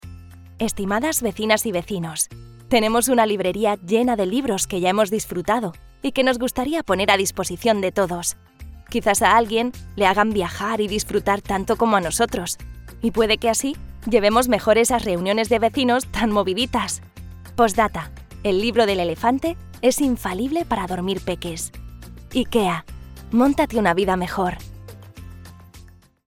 Commercial, Young, Natural, Friendly, Warm
Corporate